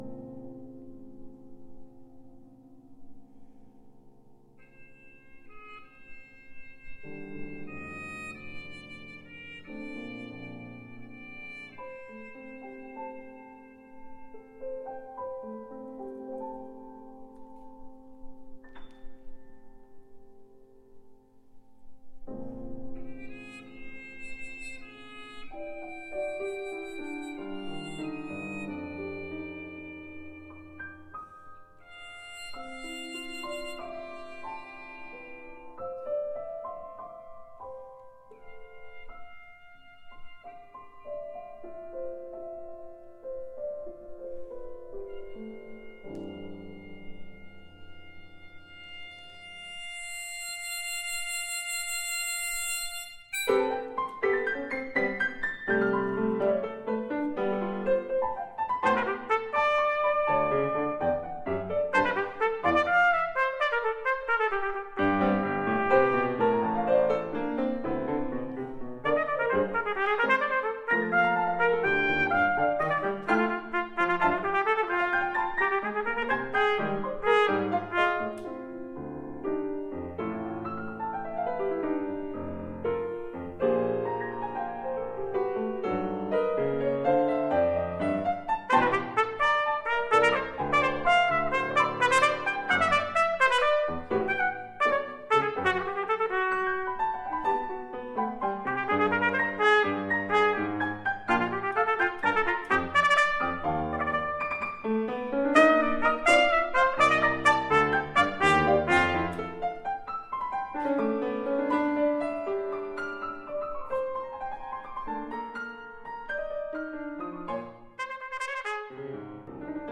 I chose to play the performance on this recording on my Eb trumpet.
The excerpts below are taken from a recital I gave at UW=Platteville, Fall 2012.  I was playing an Eclipse C, Eclipse Flugel and Blackburn Eb.
The piece opens with trumpet in Harmon mute.
After this brief lyric opening, the movement explodes in to tons of odd meter, rhythmic complexity.
Bradshaw-Sonata-mvt-one.mp3